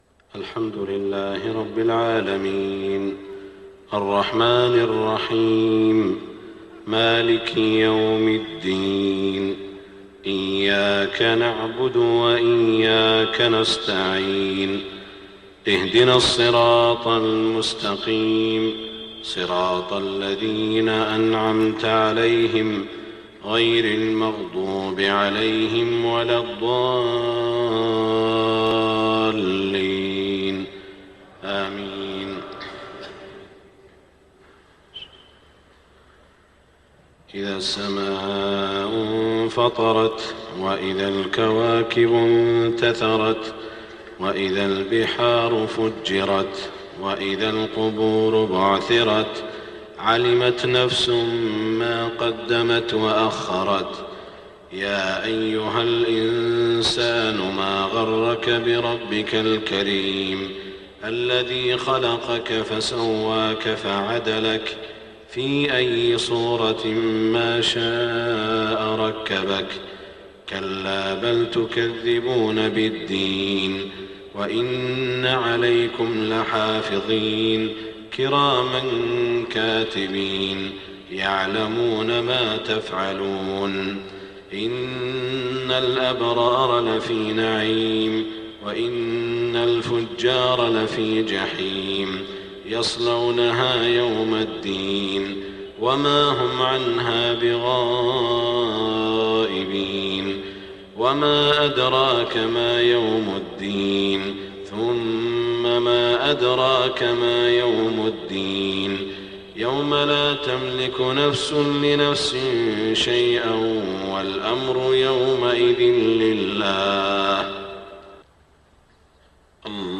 صلاة الفجر 19 ذو الحجة 1427هـ سورتي الانفطار و الأعلى > 1427 🕋 > الفروض - تلاوات الحرمين